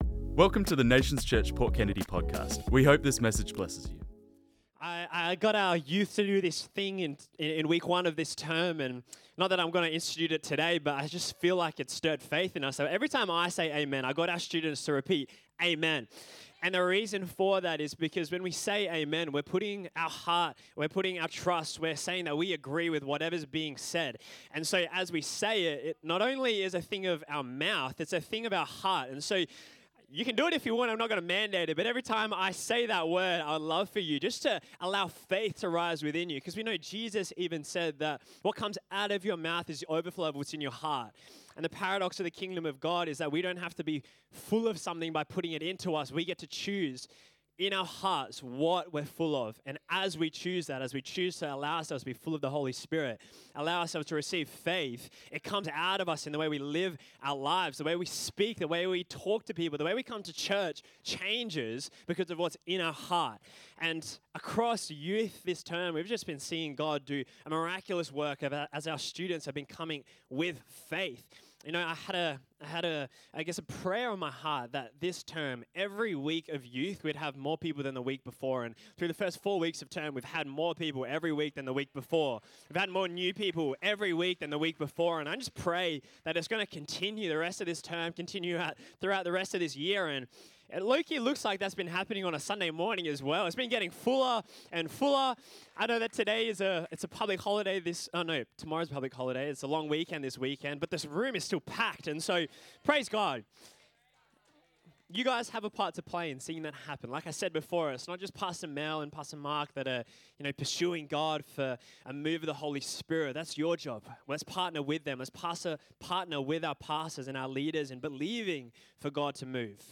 This message was preached on Sunday 1st March 2026